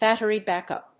battery+backup.mp3